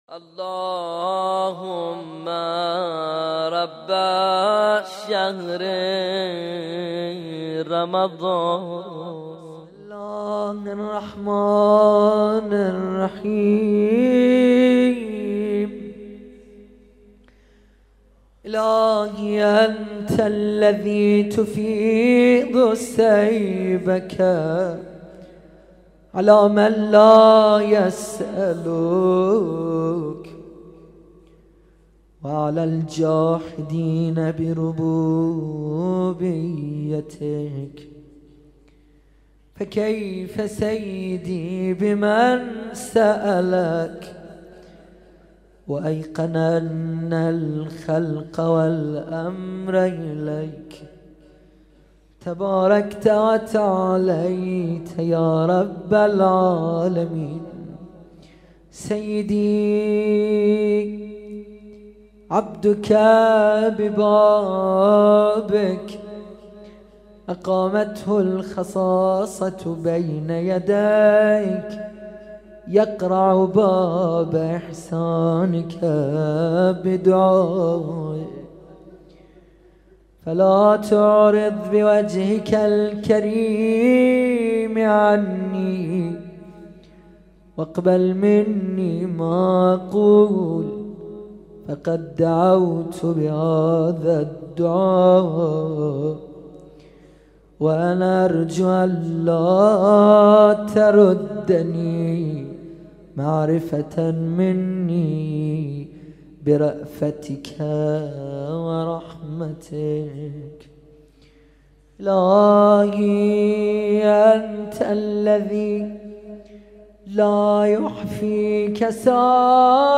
قرائت دعای أبو حمزه الثمالی (بخش پنجم)
هیأت علی اکبر بحرین